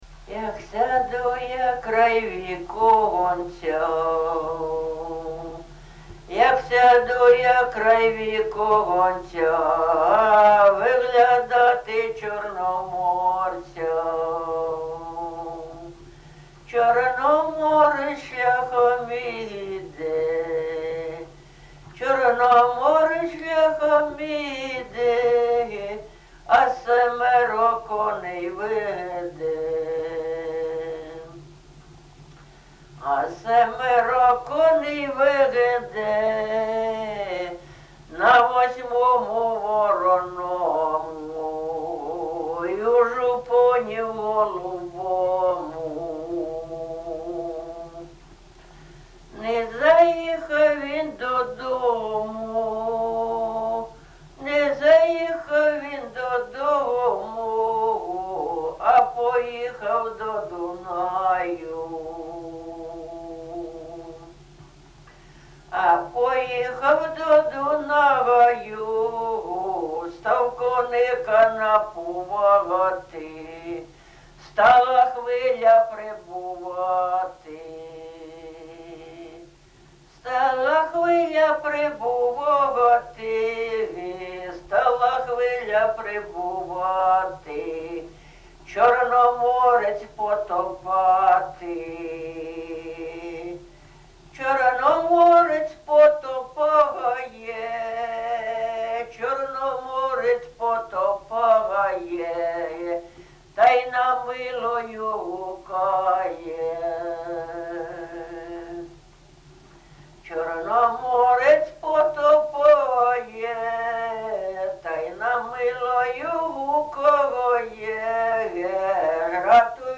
ЖанрПісні з особистого та родинного життя
Місце записус. Софіївка, Краматорський район, Донецька обл., Україна, Слобожанщина